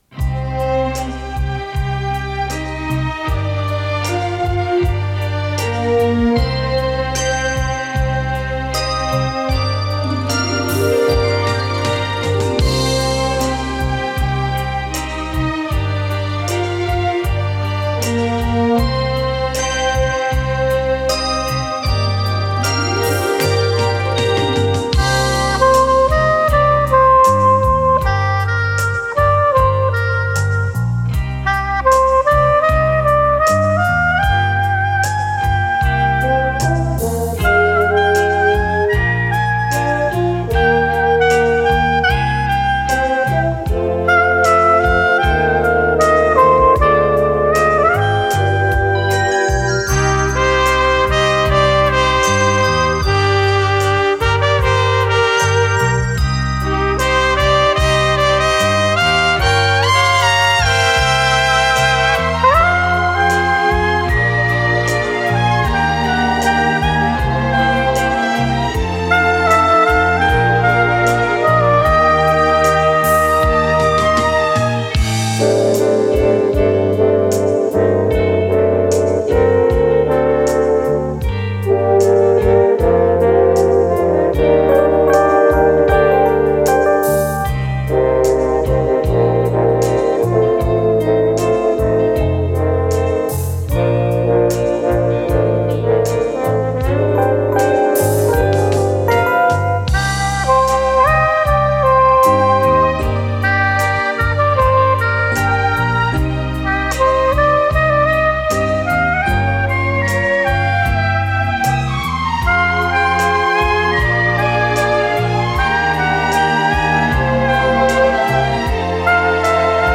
Подзаголовок(слоу-бит)
ВариантДубль моно